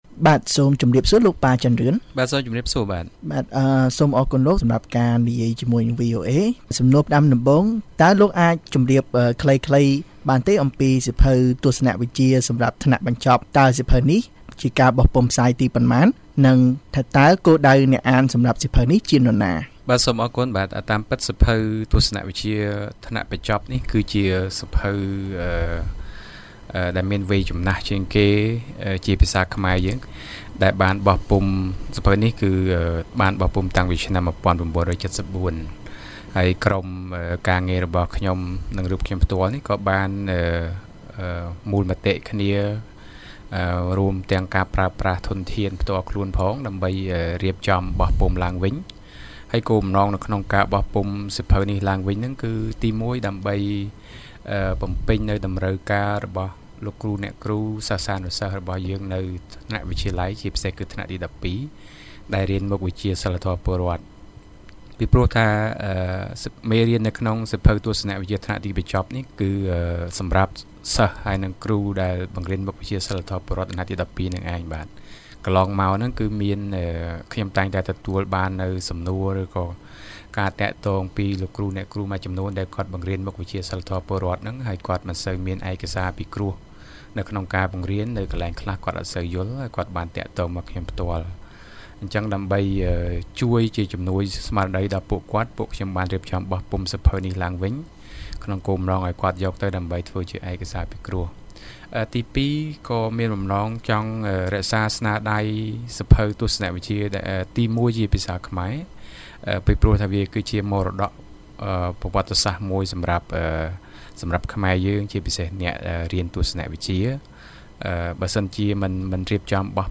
បទសម្ភាសន៍ VOA៖ សកម្មជនរៀបចំបោះពុម្ពឡើងវិញសៀវភៅមូលដ្ឋានទស្សនវិជ្ជា